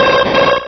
Cri de Xatu dans Pokémon Rubis et Saphir.